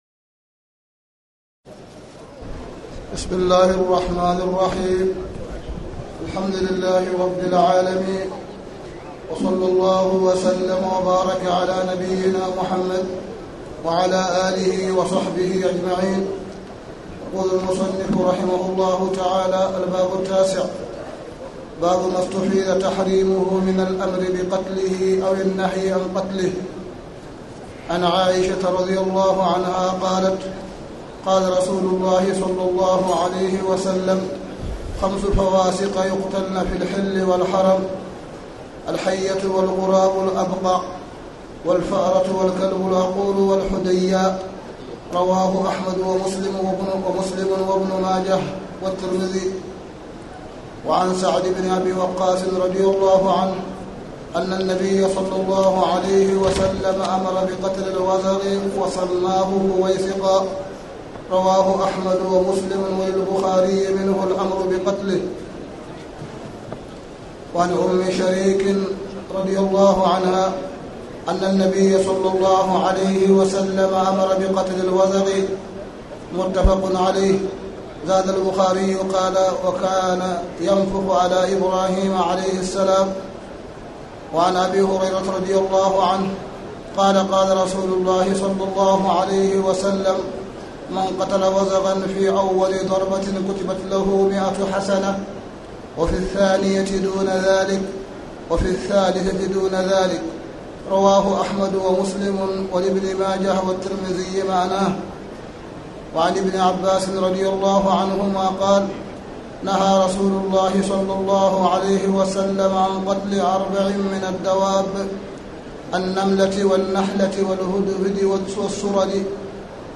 تاريخ النشر ٣٠ ذو القعدة ١٤٣٨ هـ المكان: المسجد الحرام الشيخ: معالي الشيخ أ.د. صالح بن عبدالله بن حميد معالي الشيخ أ.د. صالح بن عبدالله بن حميد باب ما أستفيد تحريمه من الأمر بقتله